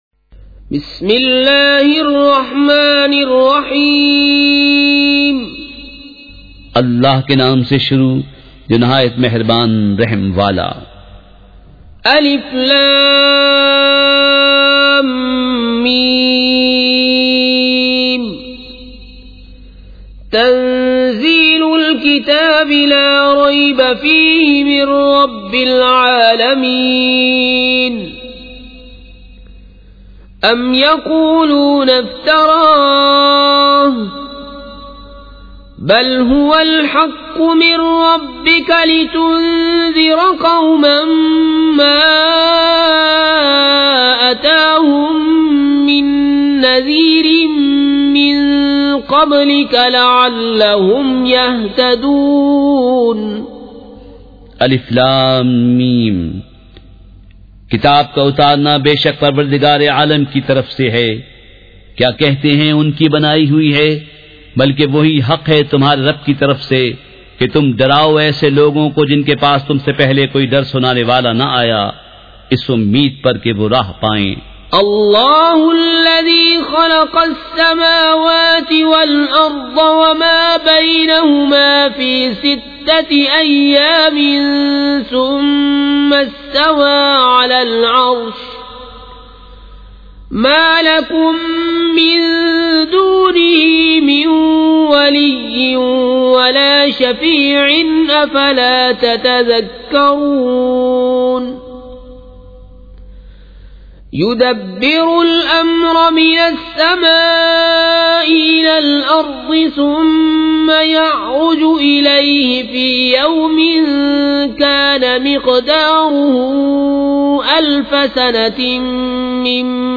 سورۃ السجدہ مع ترجمہ کنزالایمان ZiaeTaiba Audio میڈیا کی معلومات نام سورۃ السجدہ مع ترجمہ کنزالایمان موضوع تلاوت آواز دیگر زبان عربی کل نتائج 1705 قسم آڈیو ڈاؤن لوڈ MP 3 ڈاؤن لوڈ MP 4 متعلقہ تجویزوآراء